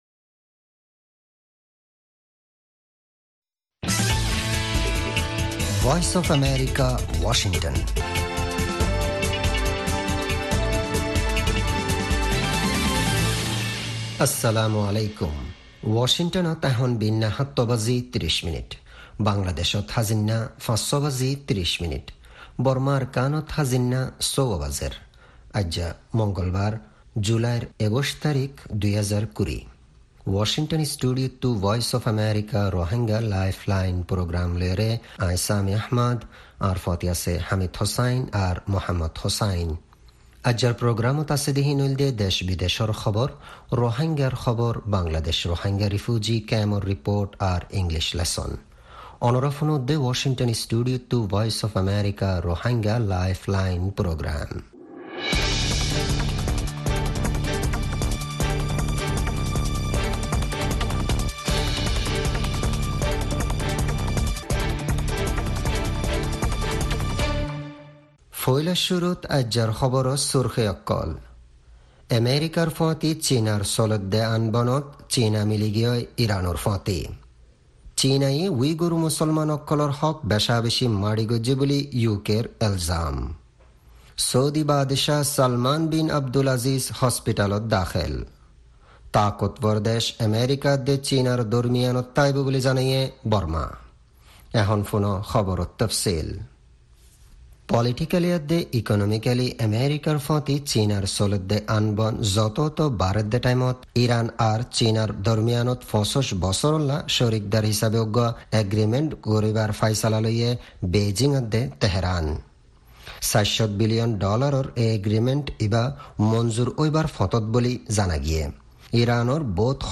Rohingya Broadcast